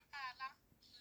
The Swedish word for pearl is pärla.
I grabbed this audio from a TV show where they talk about pearls so you can hear how it’s pronounced over here.